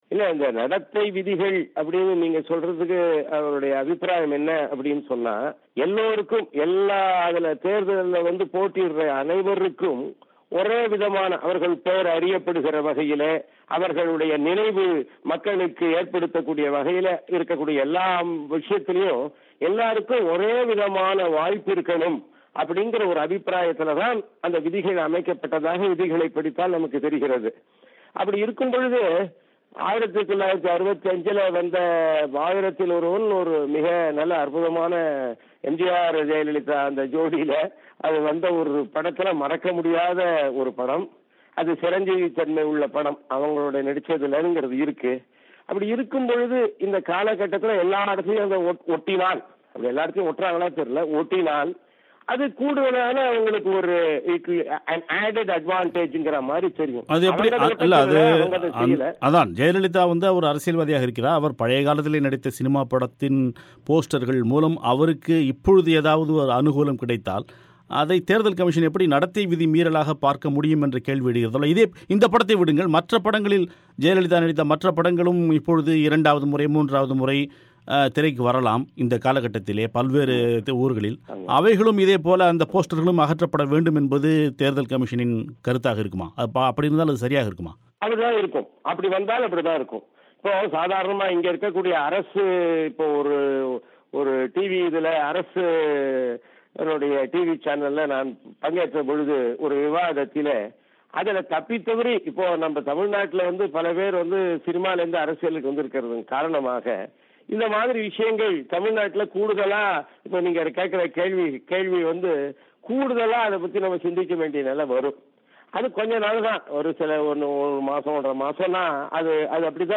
பேட்டி